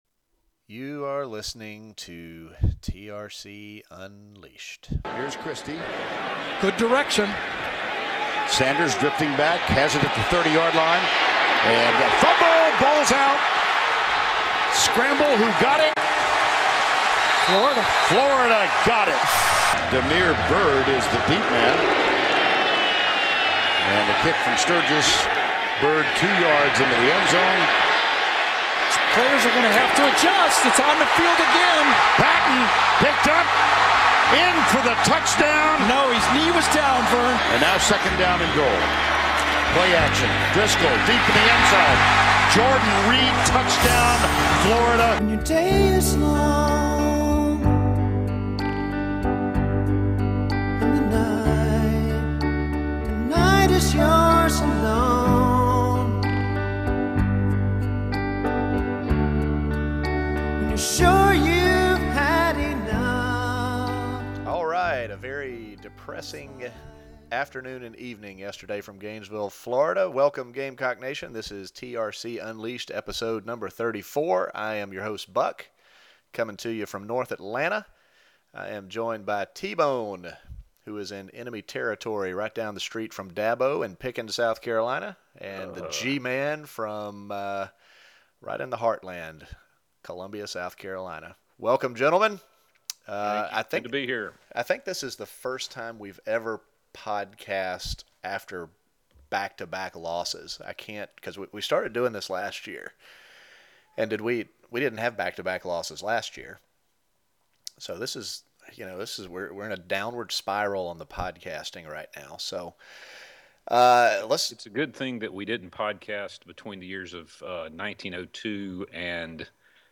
It’s probably the most feisty episode of TRCU ever